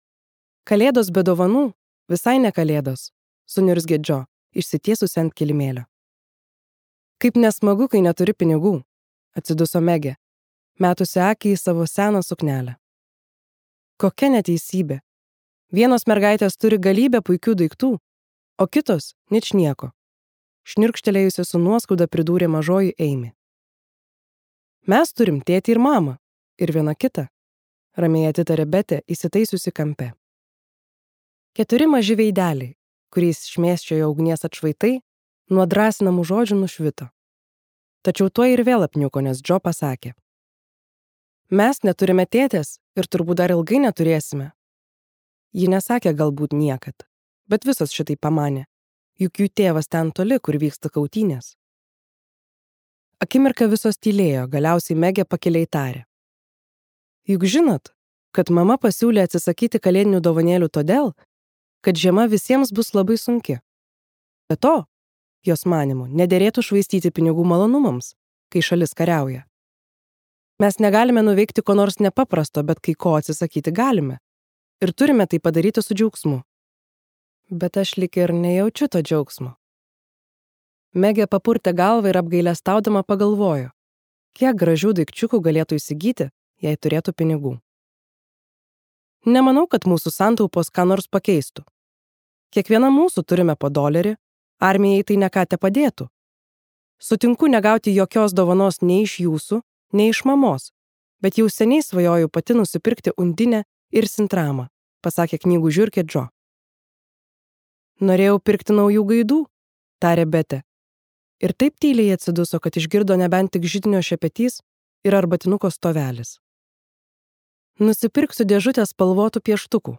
Mažosios moterys | Audioknygos | baltos lankos